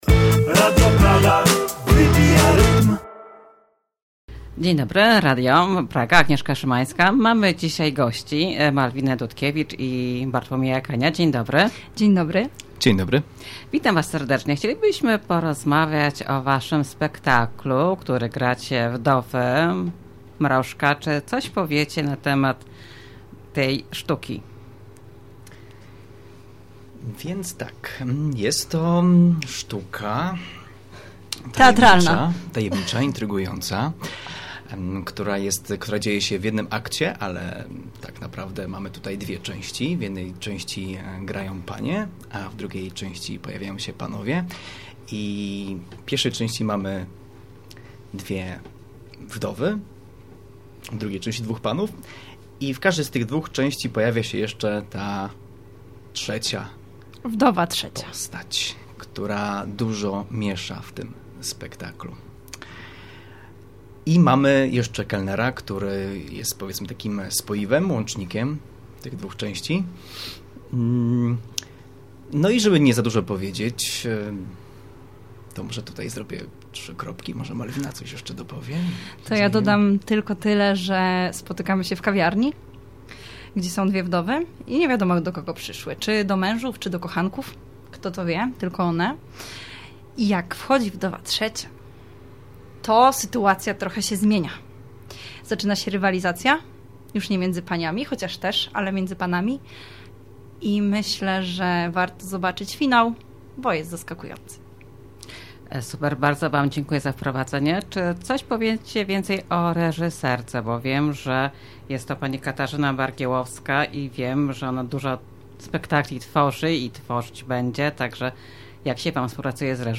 Teatr Iks odwiedził studio Radio Praga.